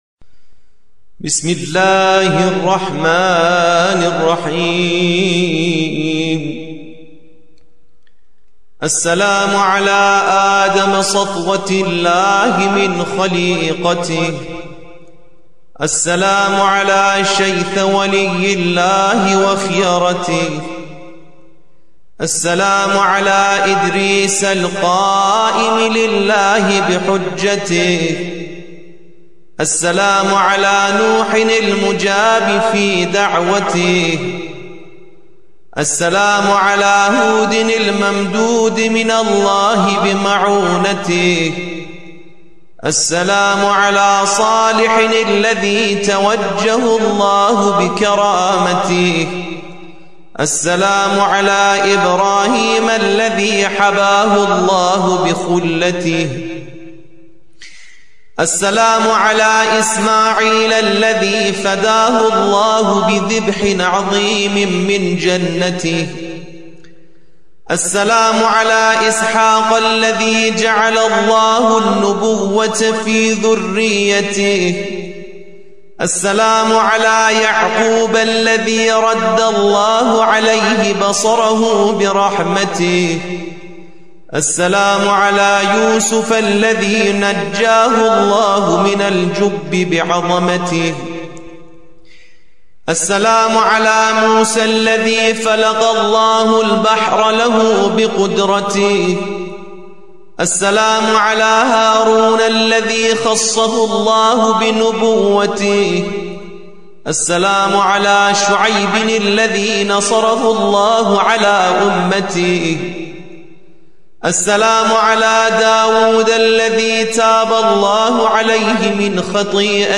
فایل صوتی زیارت ناحیه مقدسه با صدای علی فانی